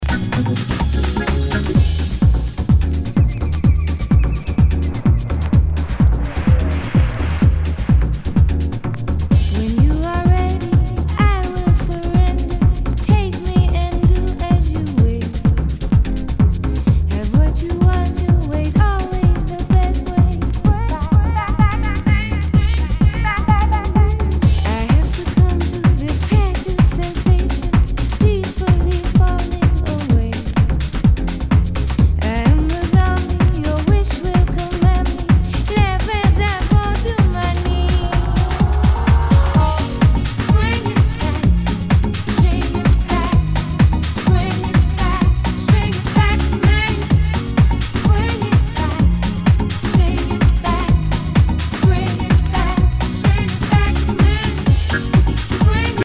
HOUSE/TECHNO/ELECTRO
ナイス！ヴォーカル・ハウス！
ジャケにスレキズ、ヨゴレあり。全体にチリノイズが入ります